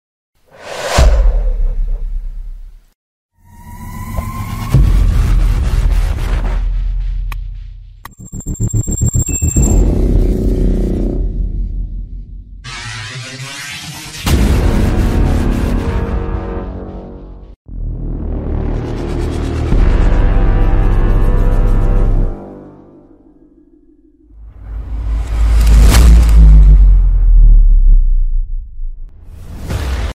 Efectos de sonidos CINEMÁTICOS e sound effects free download
Efectos de sonidos CINEMÁTICOS e Impactos